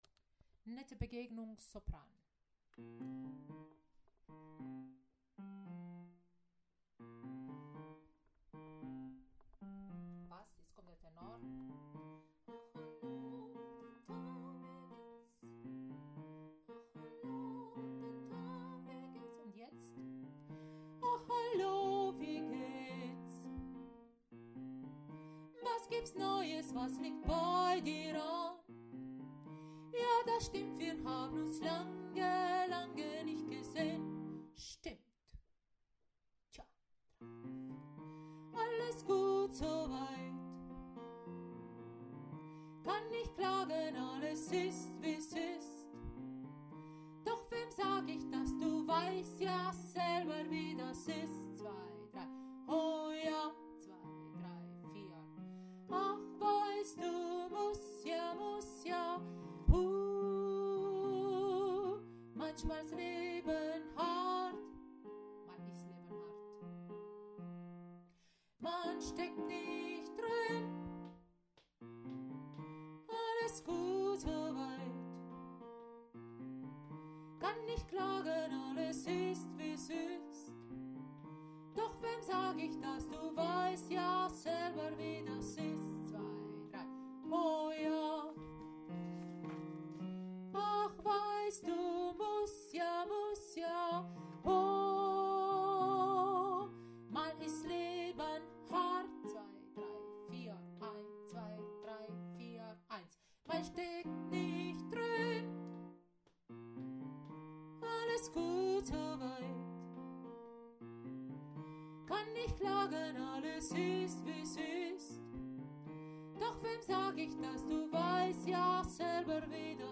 Nette-Begegnung-Sopran.mp3